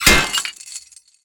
На этой странице собраны реалистичные звуки капканов разных типов: от резкого металлического щелчка до глухого захлопывания.
Капкан - Альтернативный вариант 2